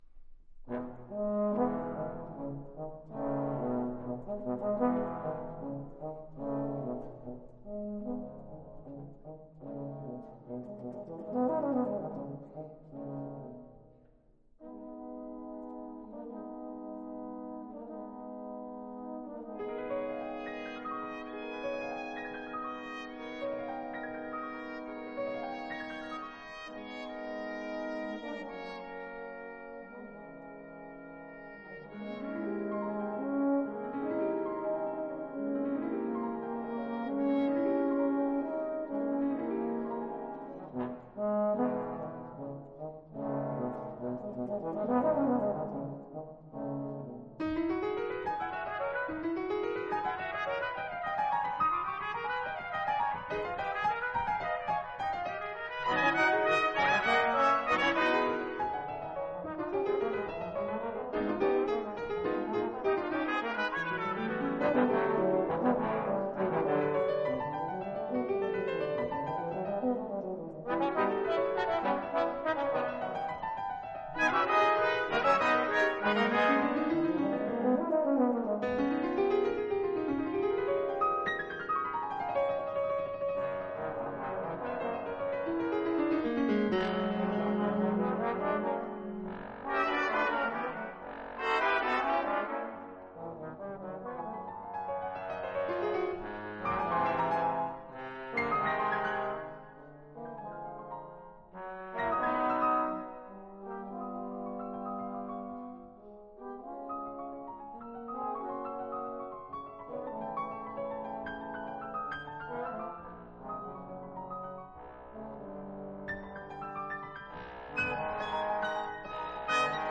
Et opptak med Bergen Filharmonien, Janáček sin venstrehåndskonsert for Piano og Kammerensemble. Solist: Jean-Efflam Bavouzet. Dirigent: Edward Gardner